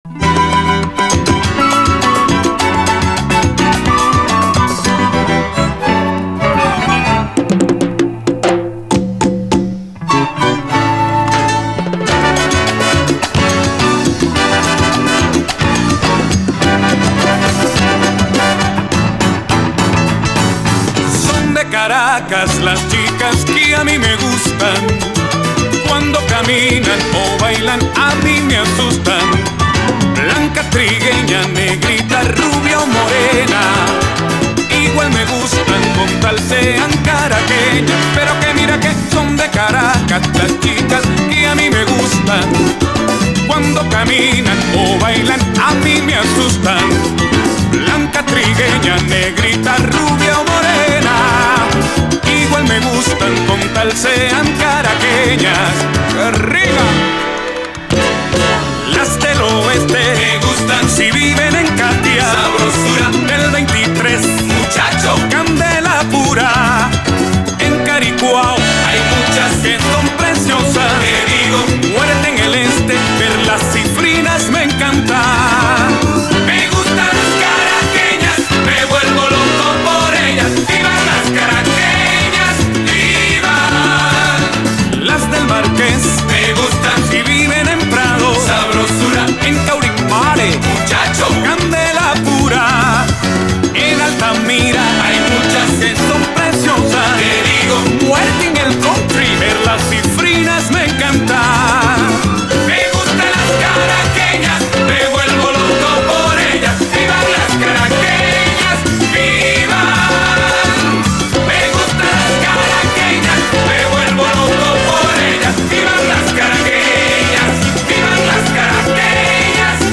SALSA, LATIN
ベネズエラのラテン音楽ガイタの代表格
エモーショナルに歓喜が疾走する最高ラテン・ダンサー
豊かな音楽性で圧倒します。